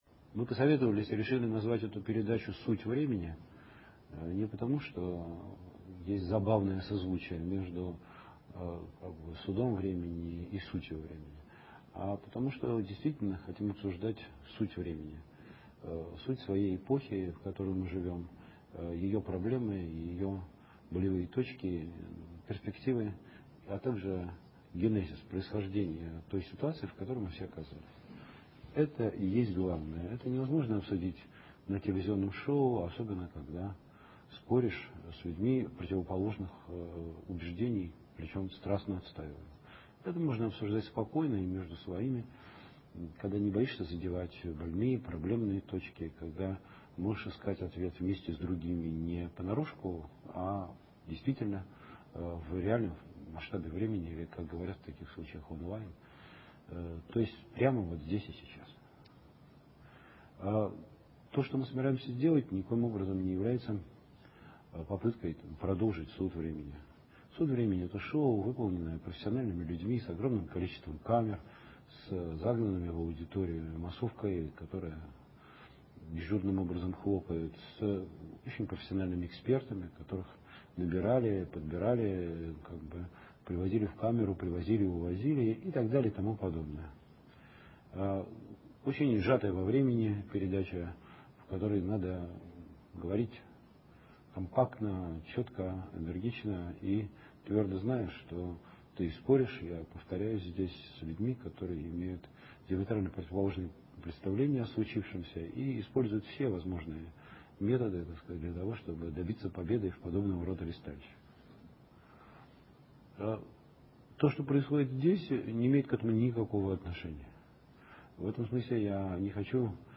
Передача без истерик, без спецэффектов, без облётов камерой, но с чрезвычайной плотностью информации и с довольно высокой сложностью политико-философских построений, многоплановостью взглядов и целостностью осмысления.
Передача начинается с довольно монотонного вступления, но где-то с десятой-одиннадцатой минуты начинается содержательная часть, которая уже весьма образна, крайне глубока и одновременно широка.